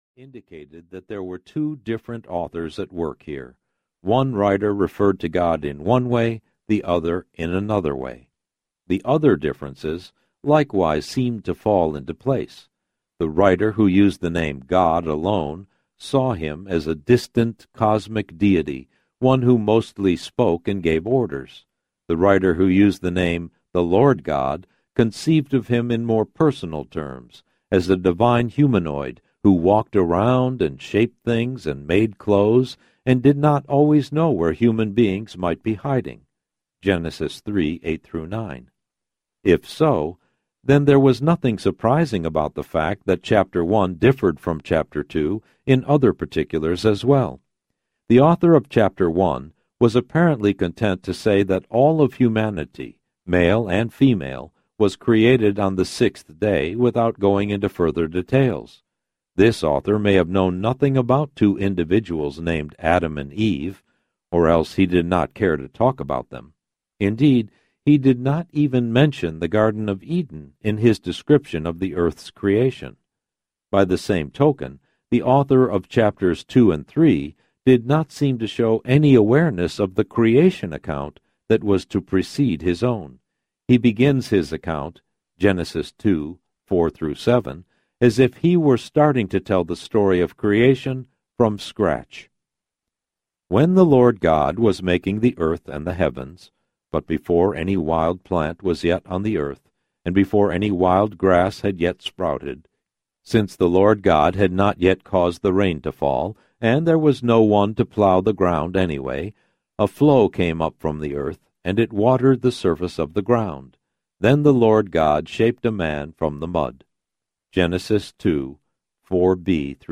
How to Read the Bible Audiobook
– Unabridged